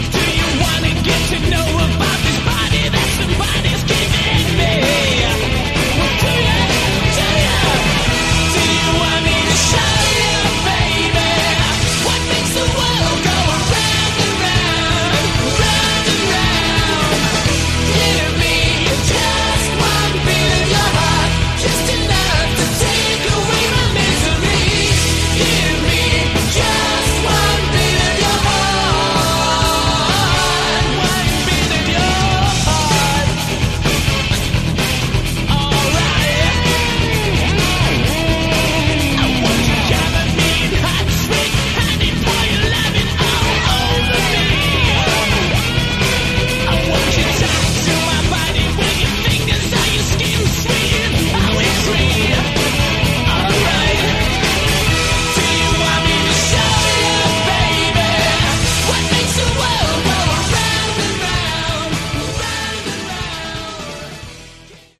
Category: Glam
Vocals
Bass
Guitar
Drums
Keyboards